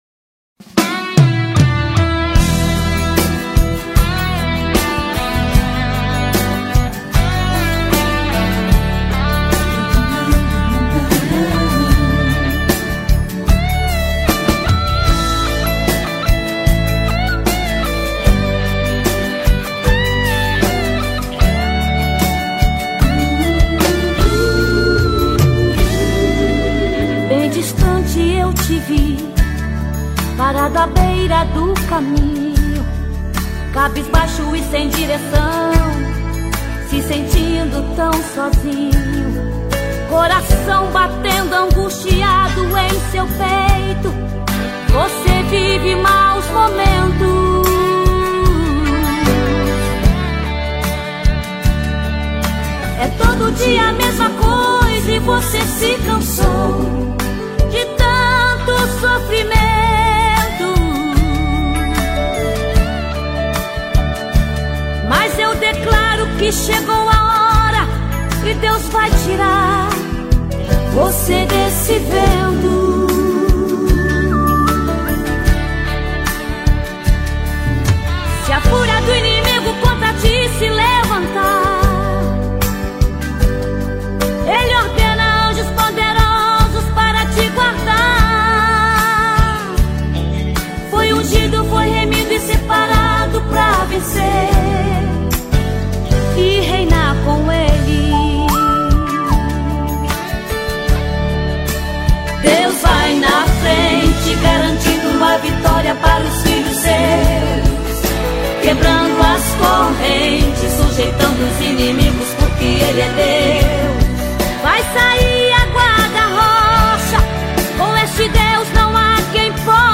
Gospel 2003